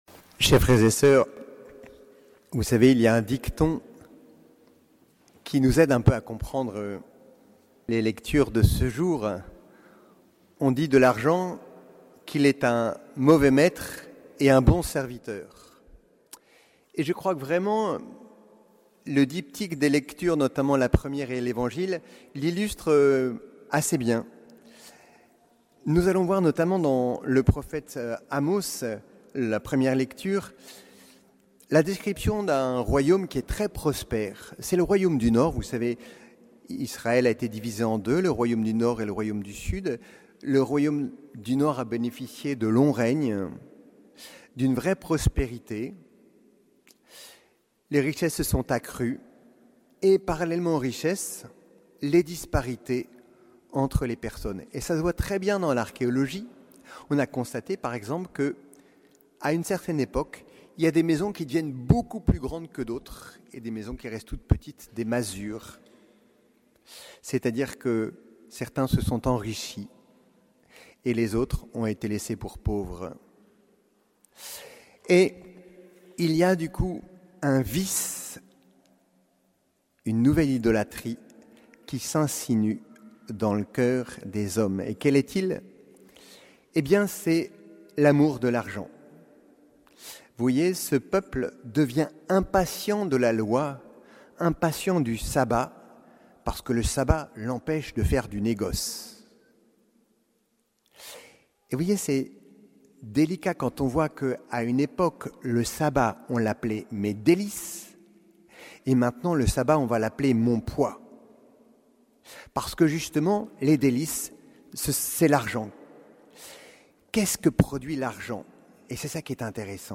Homélie du 25e dimanche du Temps Ordinaire